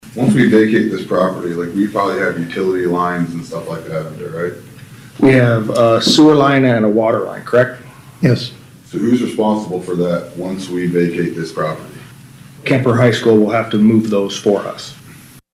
During the public hearing, Ward 2 Councilman Jason Atherton asked City Manager Aaron Kooiker which entity is responsible for buried utilities if the council were to approve the transfer.